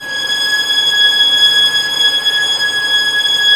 Index of /90_sSampleCDs/Roland LCDP13 String Sections/STR_Violins FX/STR_Vls Pont wh%